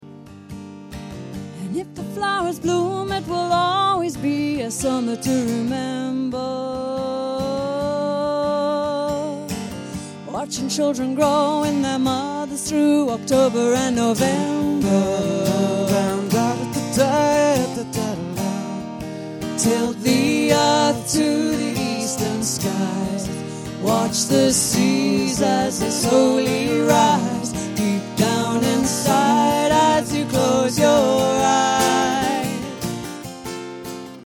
Ashington Folk Club - 01 February 2007
With some fine singing and guitar playing they did a selection of mostly their own songs - 'Monsoon', 'Garden Stones', 'The Fairest Of All Yarrow', 'Early Morning Melodies', 'Wreckers Tide', 'Marty', 'Beautiful World' and 'River Song'.